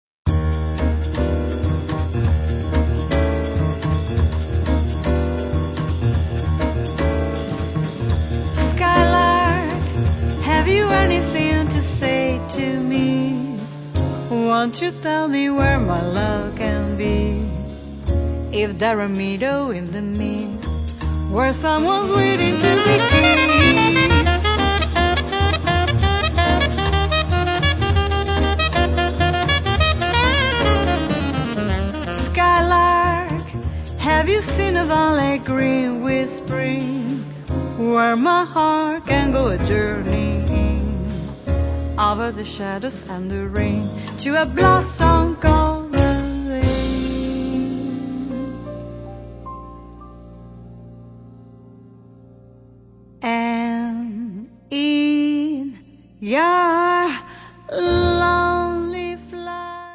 vocals
piano
alto saxophone
trumpet, flugelhorn
guitar
bass
drums